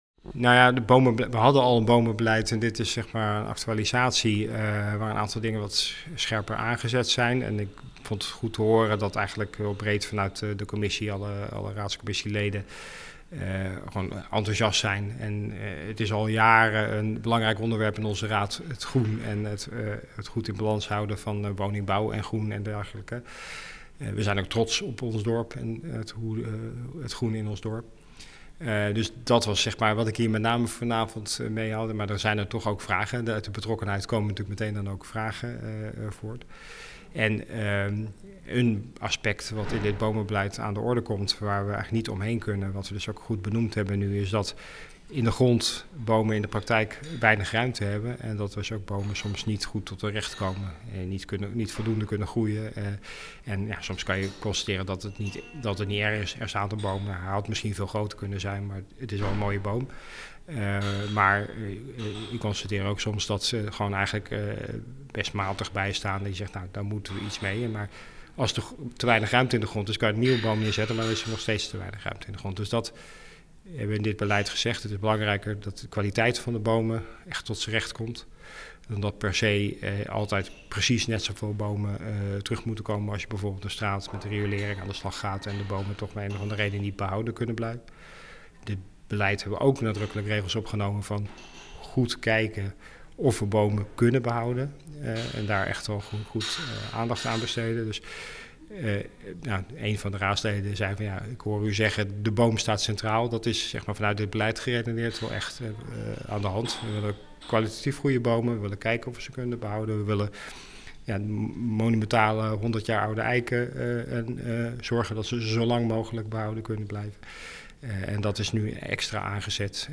Wethouder Elfred Bus